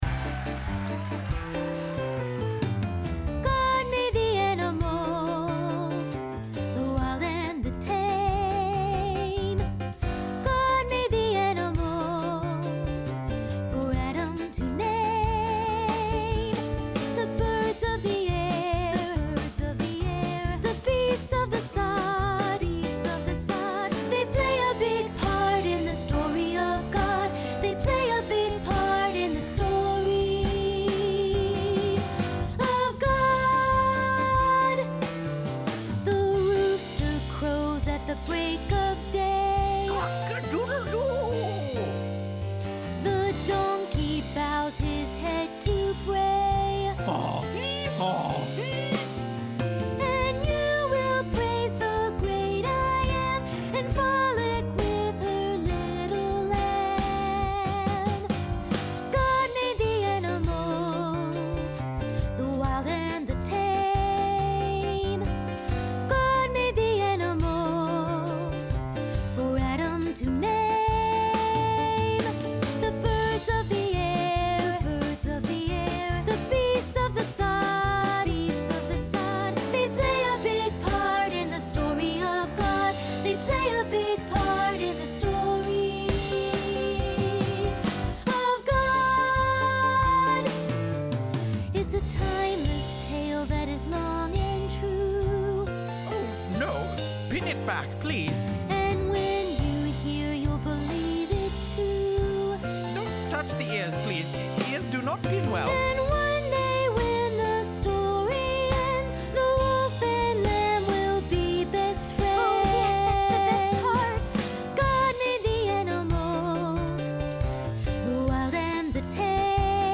Unison/Two-part with piano